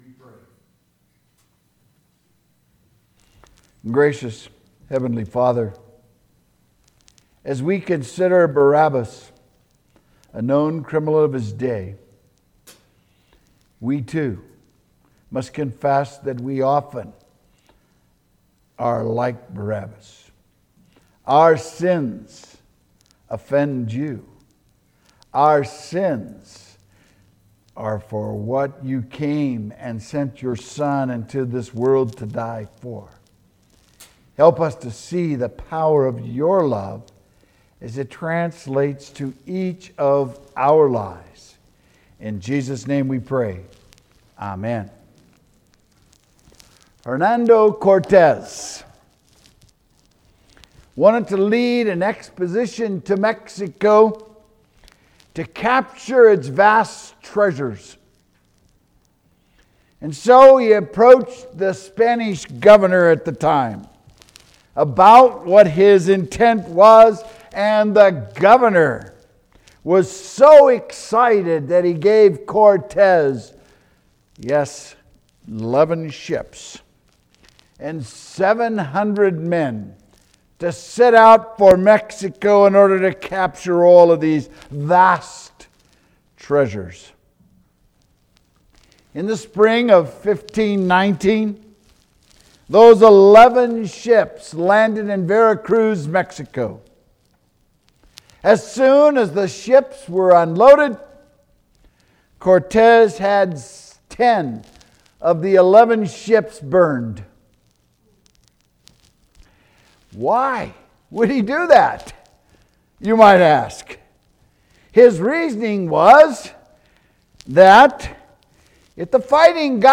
Witnesses to Christ: Barabbas March 30, 2022 Sermon